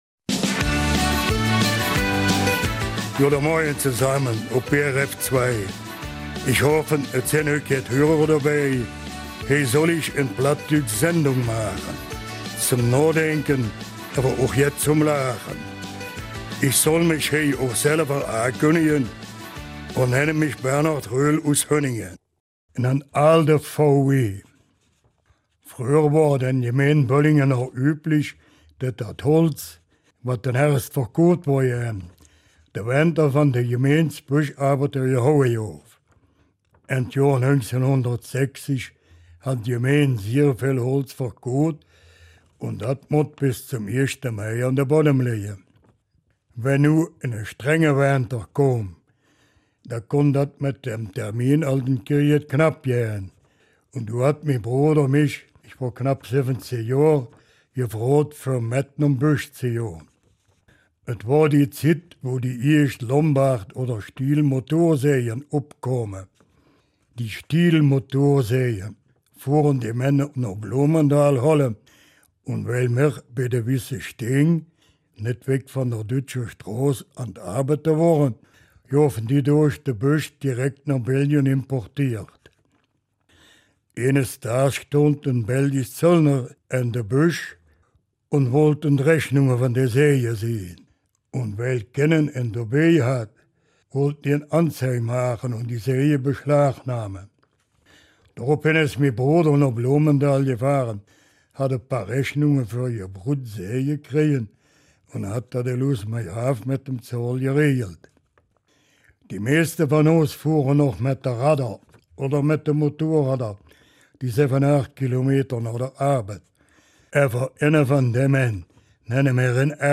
Eifeler Mundart - 4. Januar